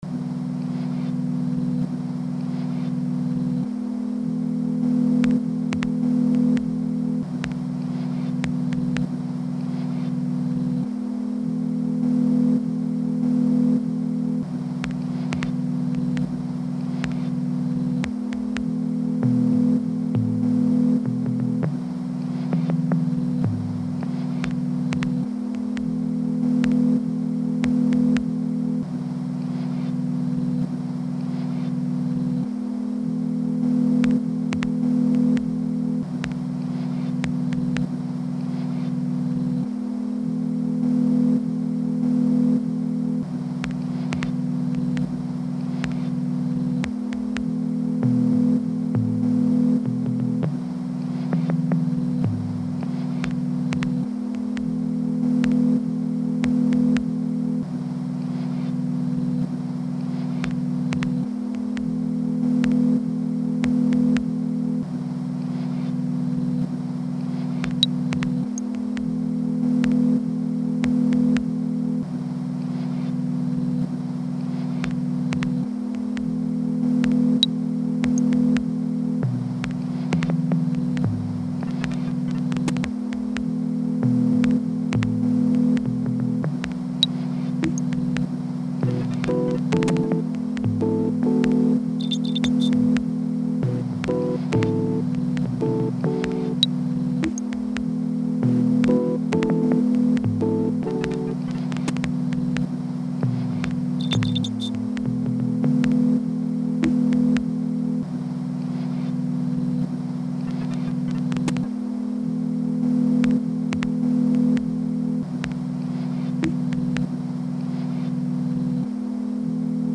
in a subtle and gentle way